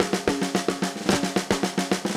Index of /musicradar/80s-heat-samples/110bpm
AM_MiliSnareA_110-03.wav